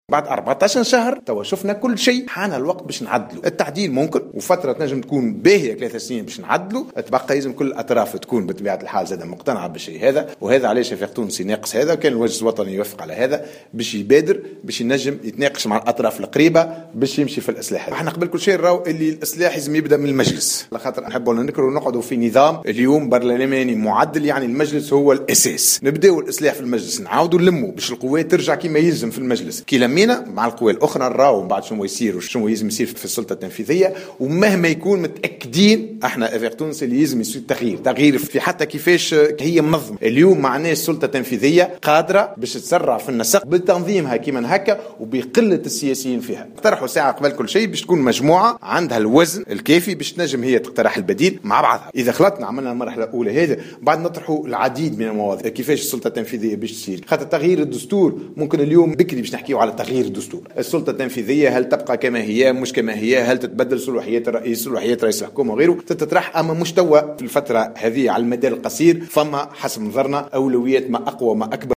قال رئيس حزب آفاق تونس ياسين ابراهيم خلال حضوره اليوم الأحد 10 أفريل 2016 أشغال الدورة العادية للمجلس الوطني لحزبه بالحمامات من ولاية نابل إن الوقت حان للتعديل بعد مرور 14 شهرا على عمل الحكومة.